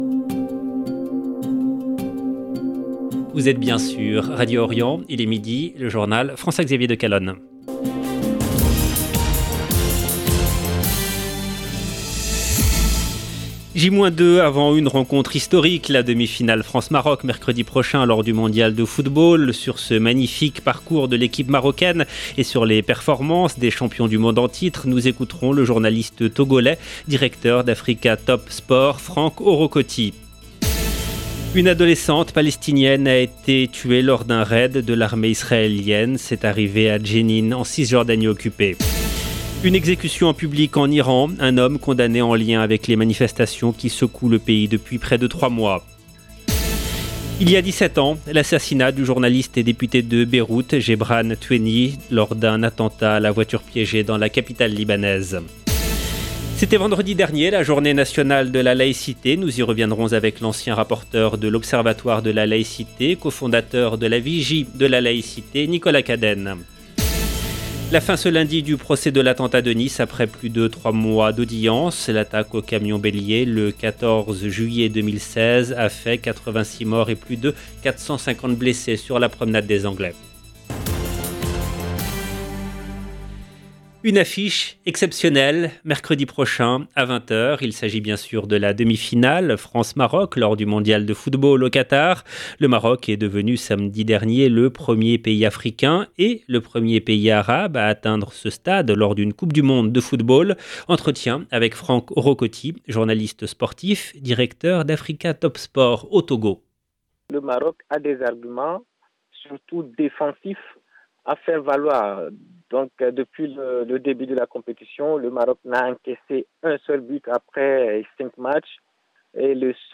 LE JOURNAL DE MIDI EN LANGUE FRANCAISE DU 12/12/22